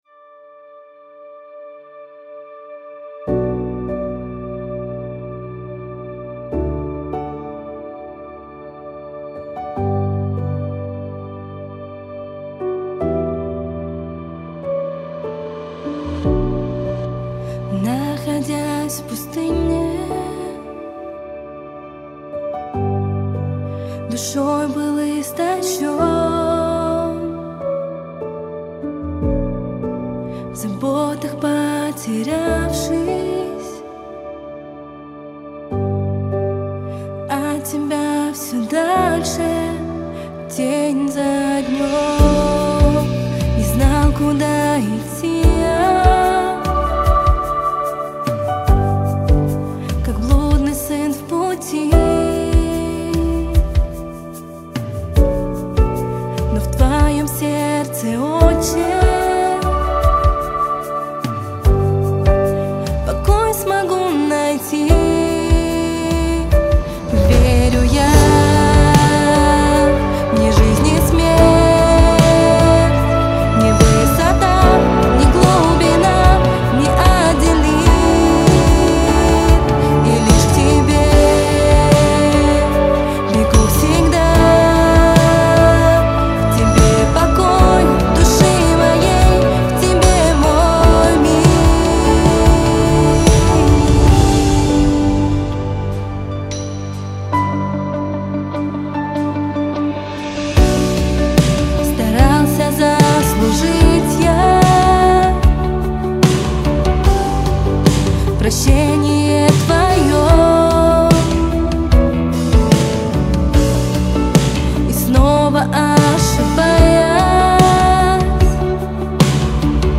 146 просмотров 151 прослушиваний 11 скачиваний BPM: 158